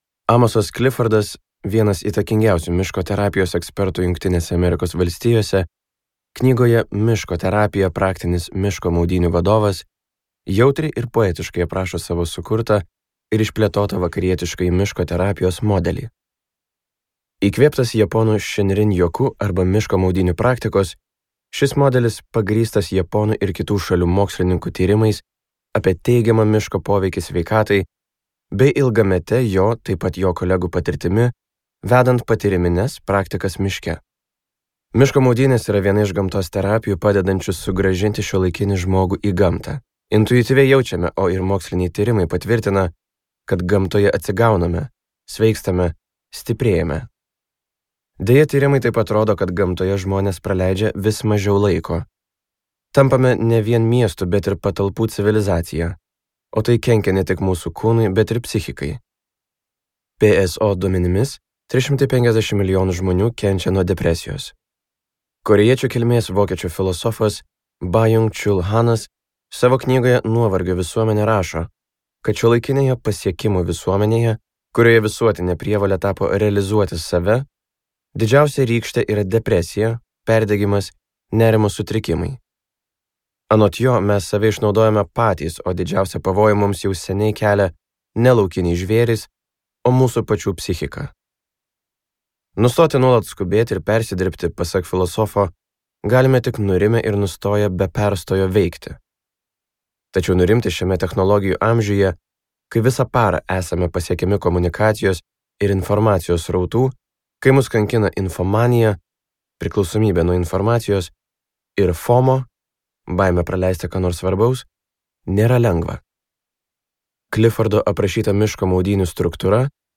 Miško terapija | Audioknygos | baltos lankos